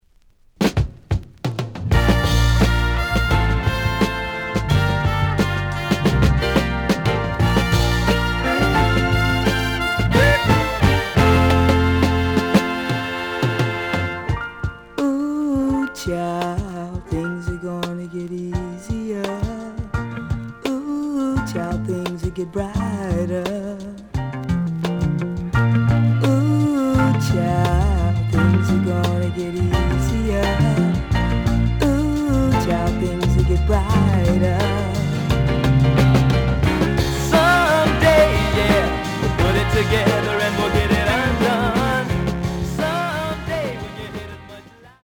The audio sample is recorded from the actual item.
●Genre: Soul, 70's Soul
Slight sound cracking on both sides.